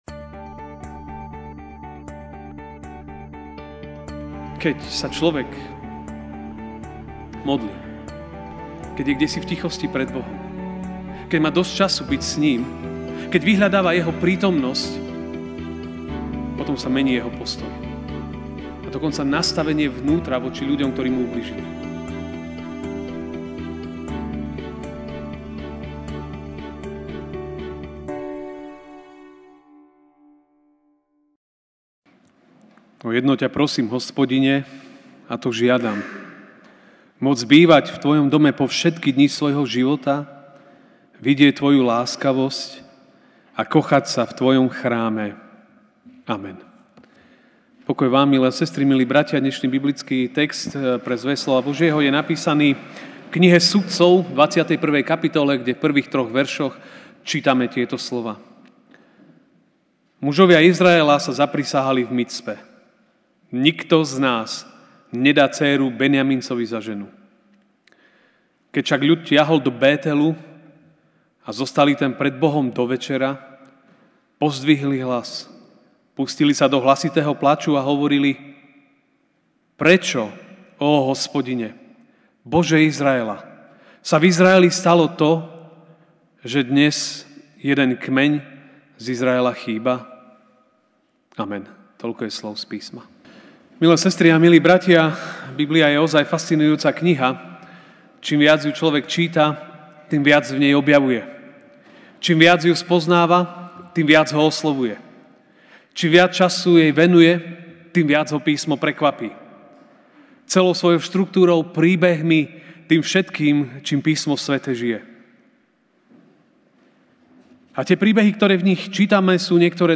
Ranná kázeň